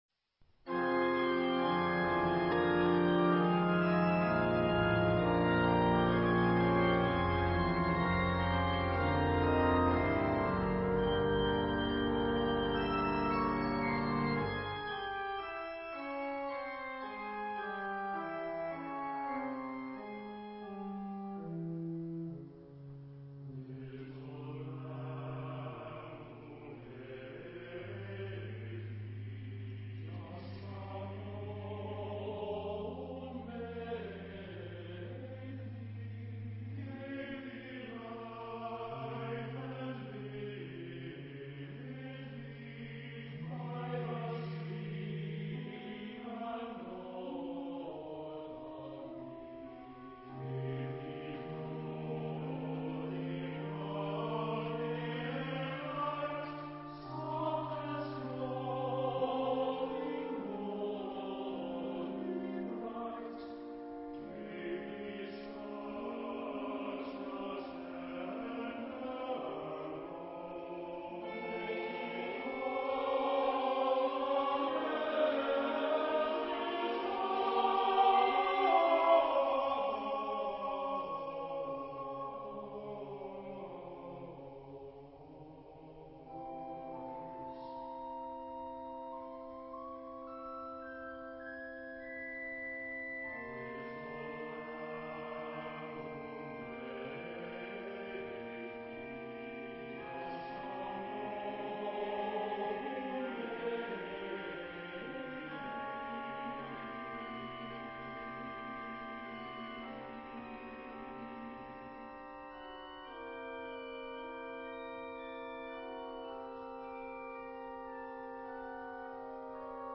Epoque: 20th century
Genre-Style-Form: Antiphon ; Sacred
Type of Choir: SATB  (4 mixed voices )
Instruments: Organ (1)